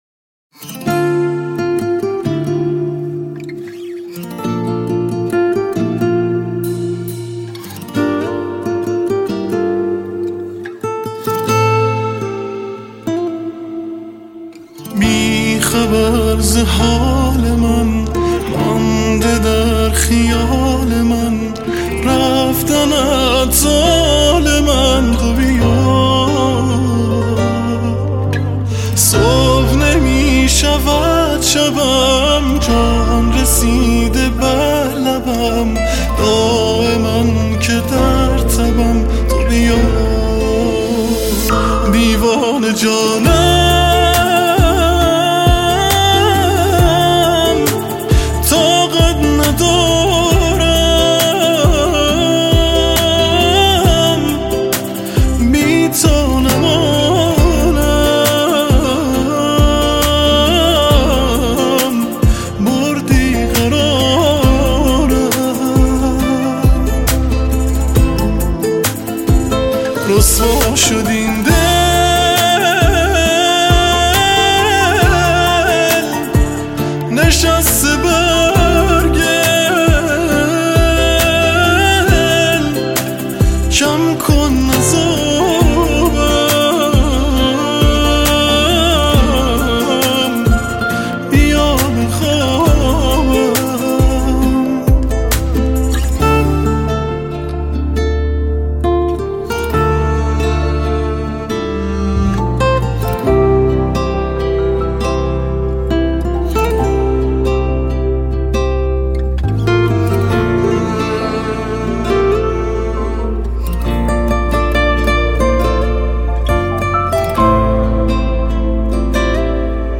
دسته : الکترونیک